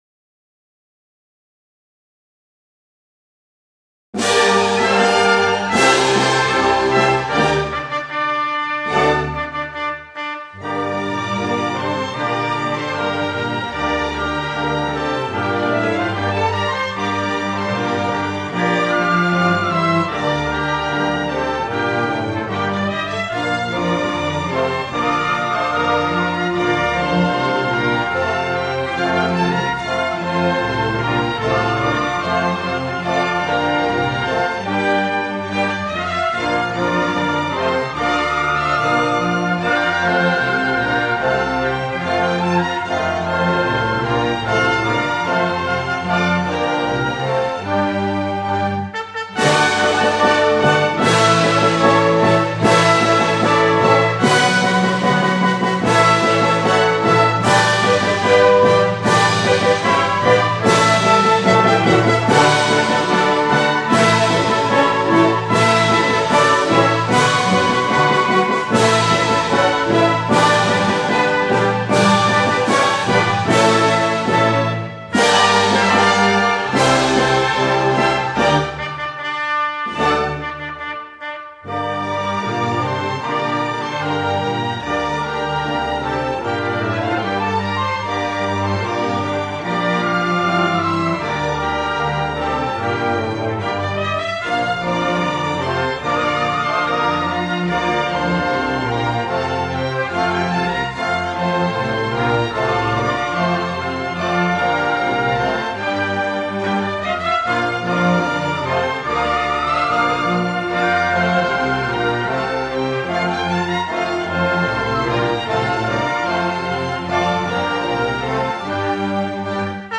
(инструментальная версия)